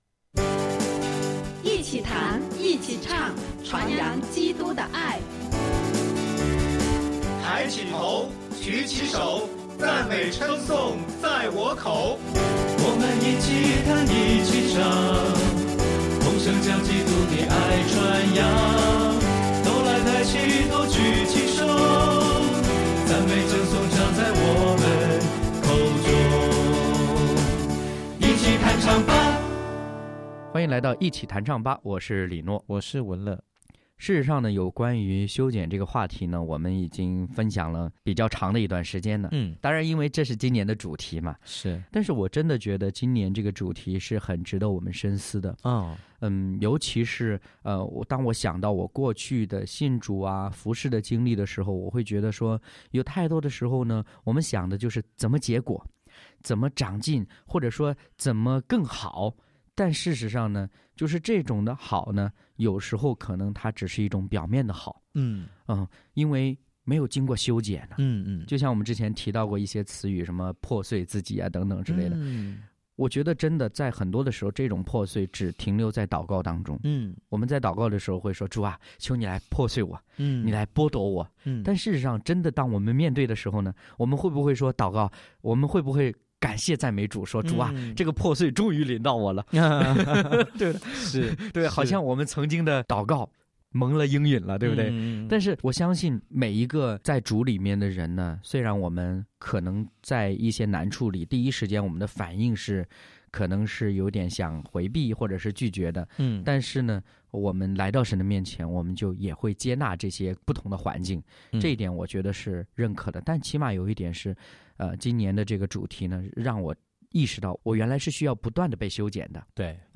敬拜分享：虽有艰难，祂赐平安；诗歌：《赞美全能主》、《在基督里有平安》